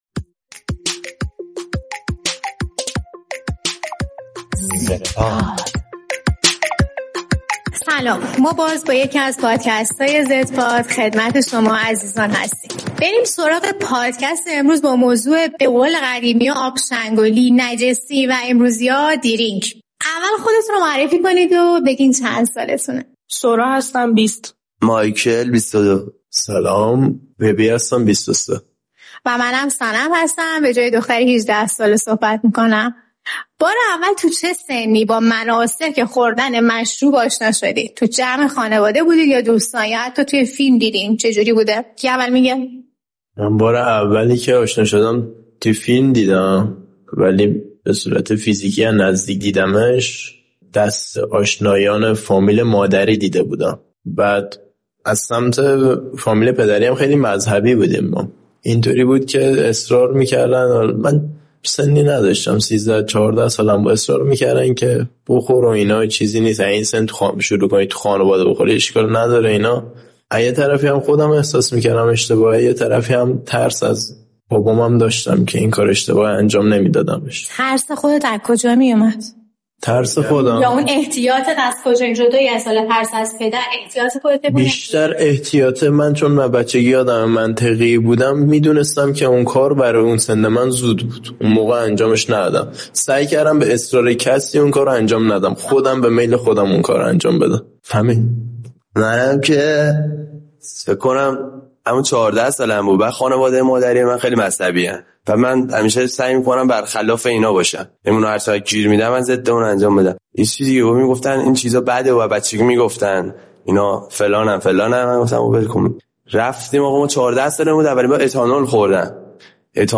در این قسمت بچه‌های زدپاد درباره مشروبات الکلی گفت‌وگو می‌کنند، اینکه آیا الکل مصرف می‌کنند و نظر آن‌ها درباره مصرف الکل چیست؟